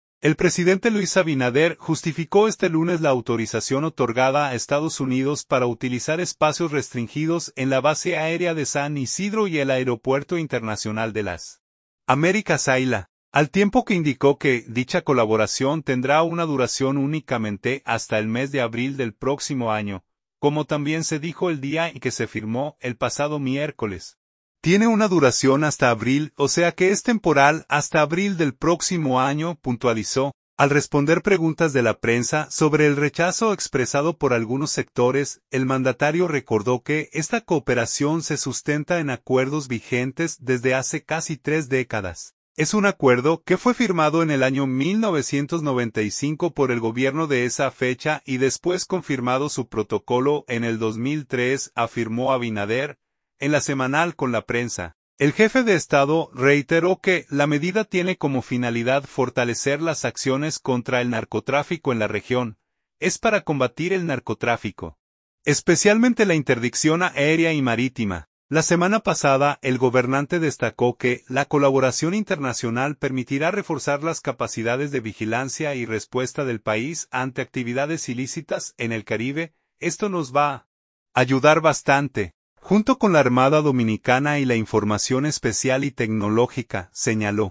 Al responder preguntas de la prensa sobre el rechazo expresado por algunos sectores, el mandatario recordó que esta cooperación se sustenta en acuerdos vigentes desde hace casi tres décadas.
En LA Semanal con la Prensa, el jefe de Estado reiteró que la medida tiene como finalidad fortalecer las acciones contra el narcotráfico en la región.